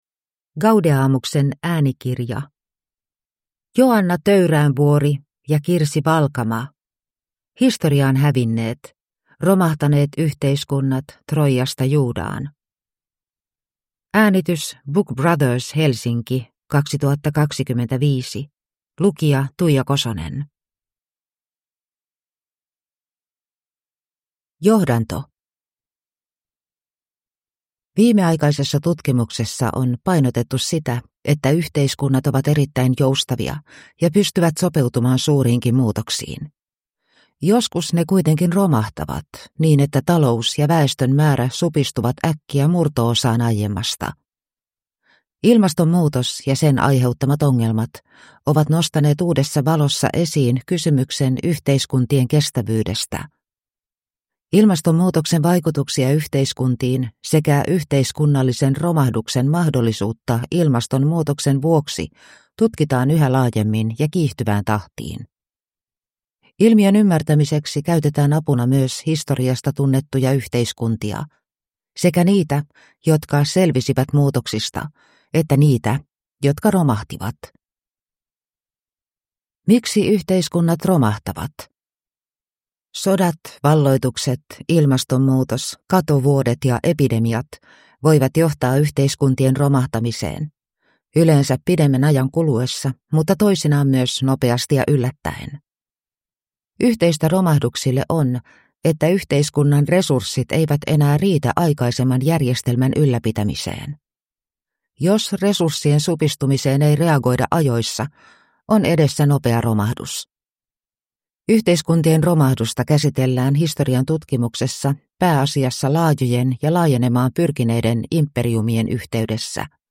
Historiaan hävinneet – Ljudbok